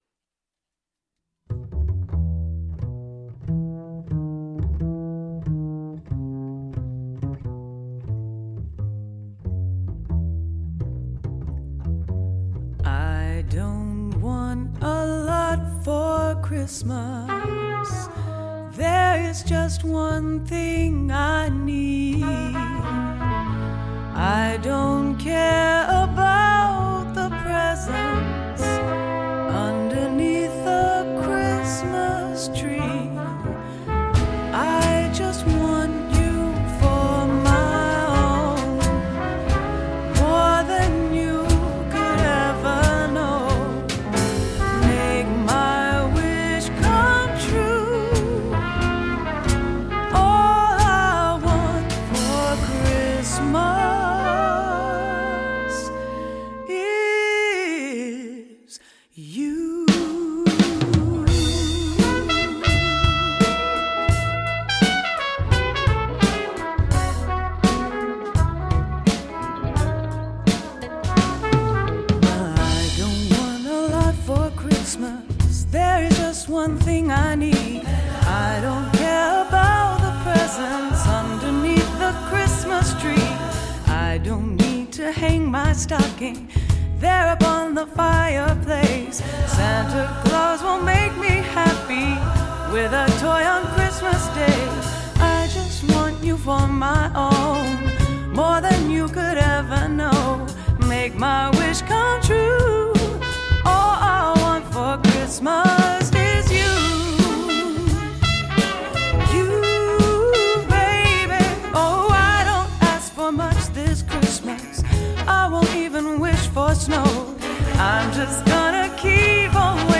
over 50 local musicians